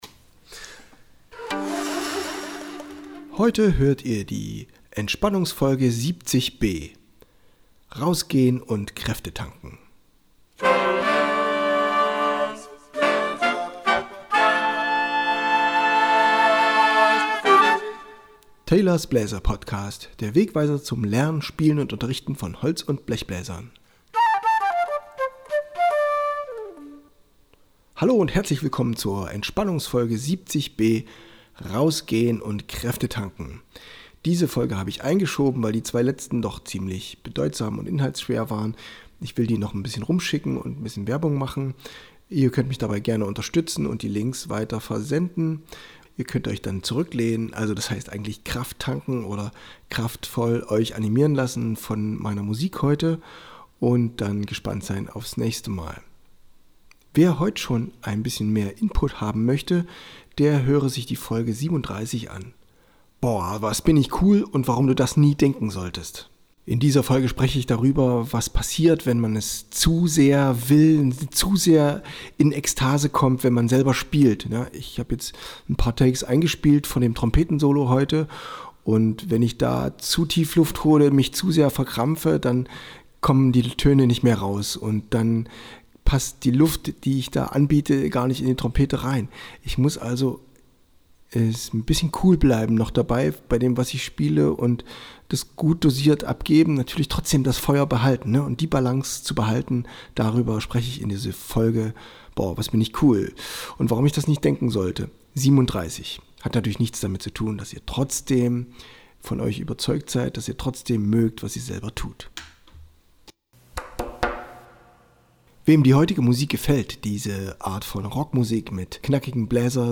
Ihr hört "Going out" i einem satten Rock+Synthie Arrangement mit Trompete, Viel Spaß!